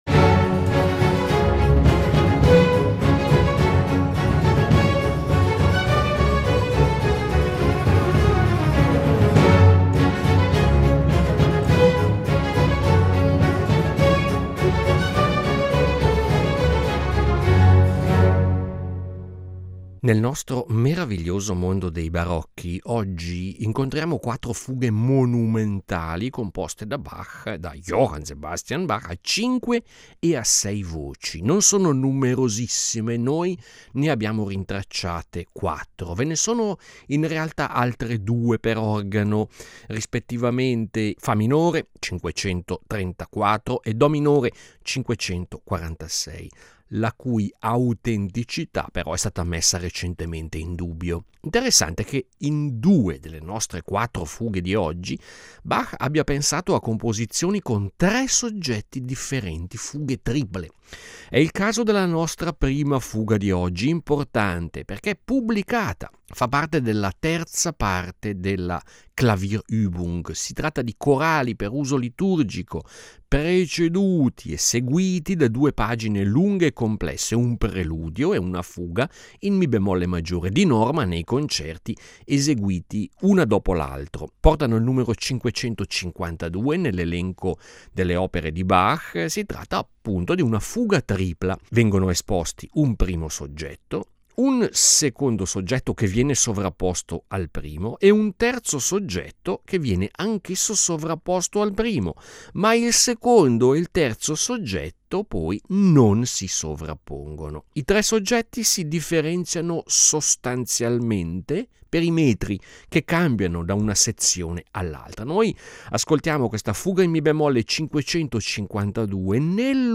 Intrigante il fatto che in due di queste complesse e dense fughe Bach utilizzi tre soggetti diversi. Gli ascolti di oggi prevederanno anche trascrizioni per organici differenti da quelli del clavicembalo o dell’organo.